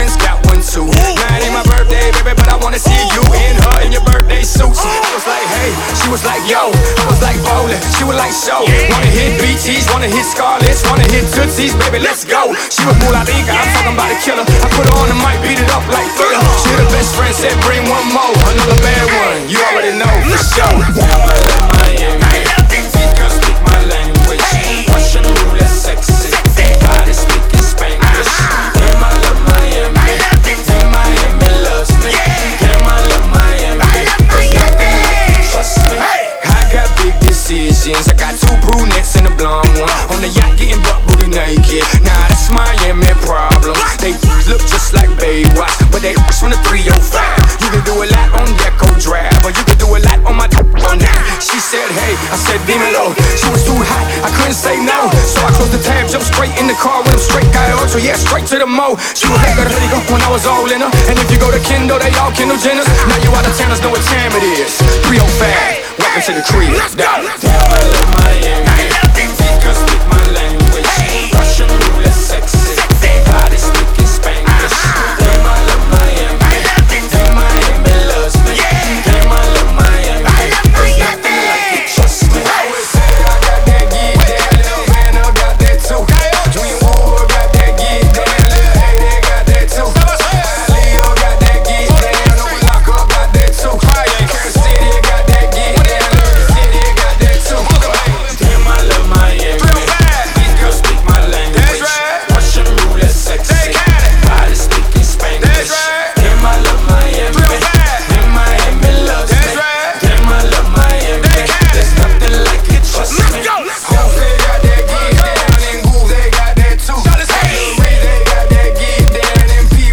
first autonomous FM capture